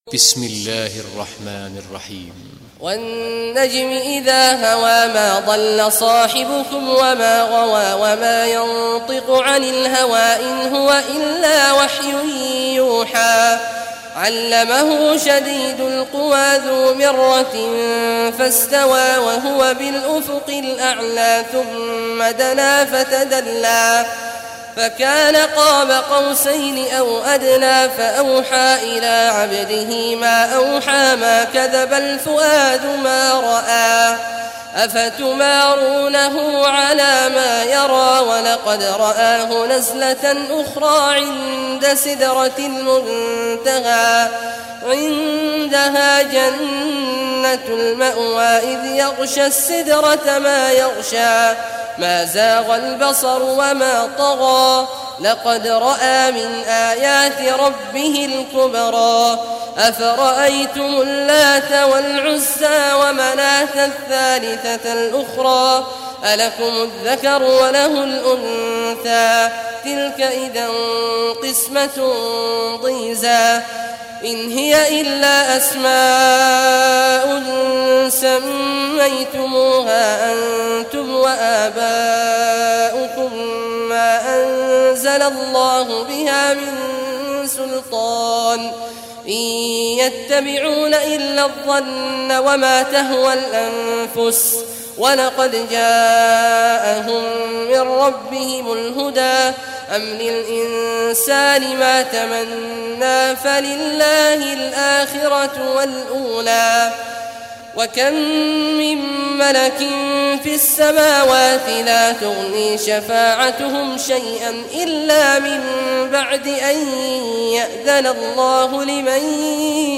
Surah An-Najm Recitation by Sheikh al Juhany
Surah An-Najm, listen or play online mp3 tilawat / recitation in Arabic in the beautiful voice of Sheikh Abdullah Awad al Juhany.